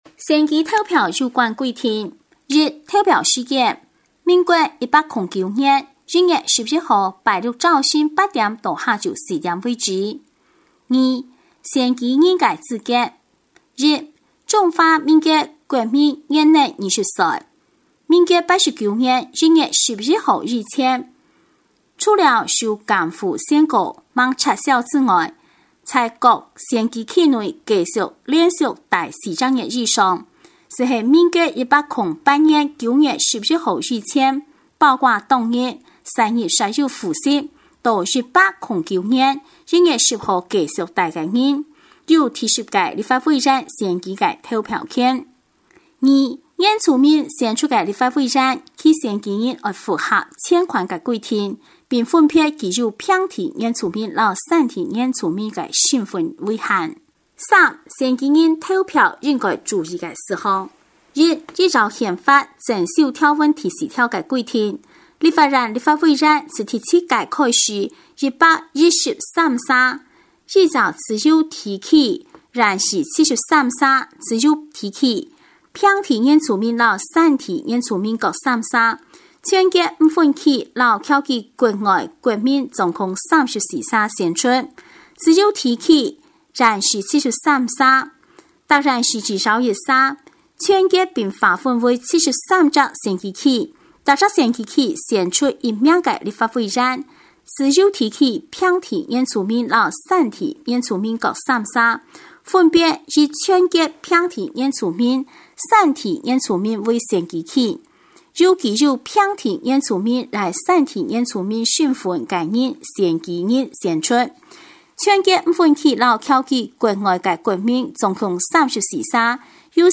新竹縣有聲選舉公報(客語) (1).mp3